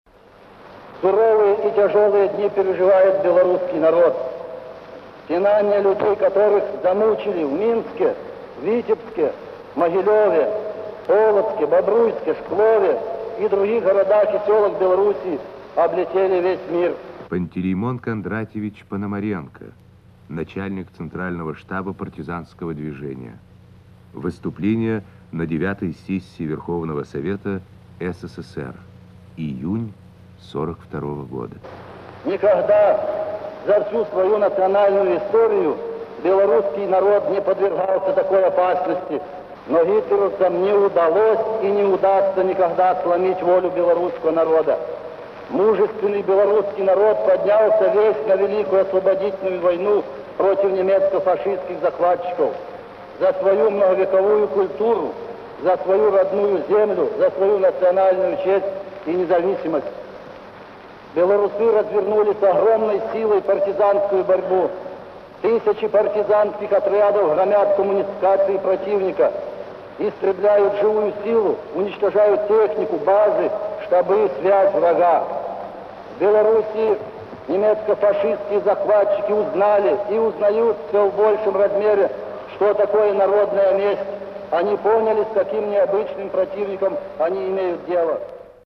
Начальник штаба партизанского движения Пантелеймон Пономаренко выступает на 9-ой Сессии Верховного Совета СССР. Архивная запись 1942 года.